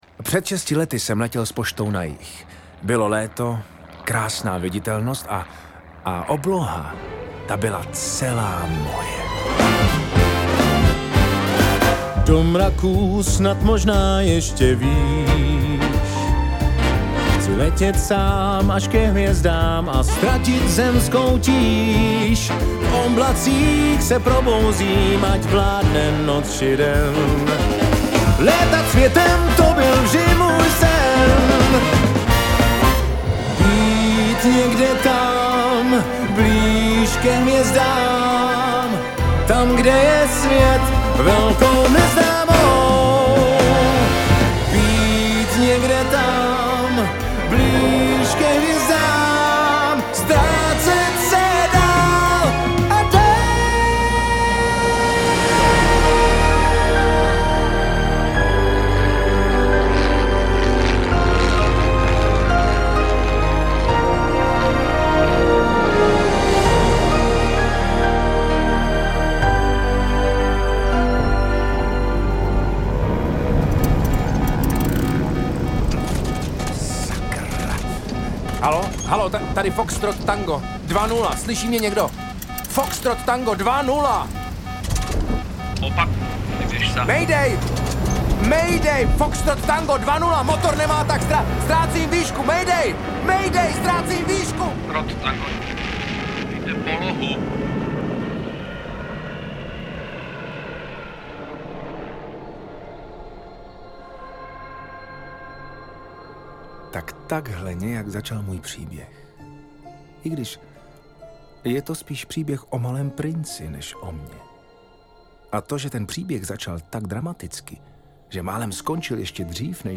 původní český muzikál